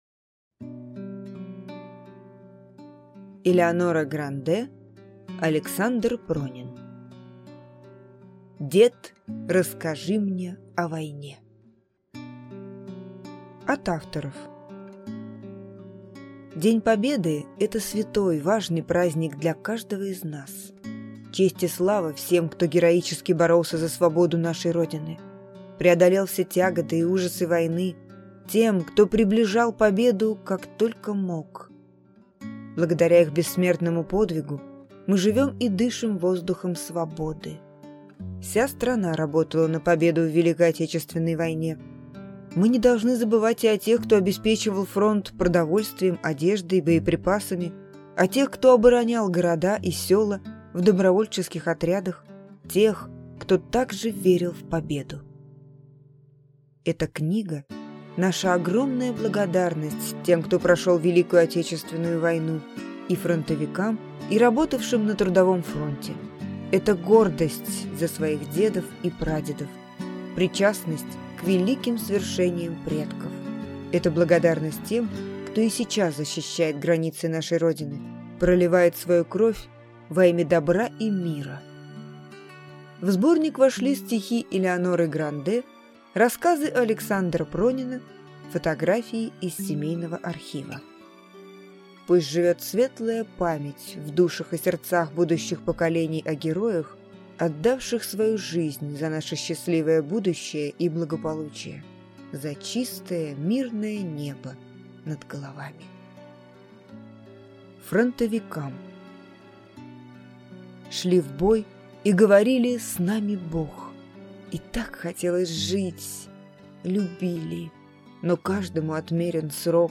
Аудиокнига Дед, расскажи мне о войне | Библиотека аудиокниг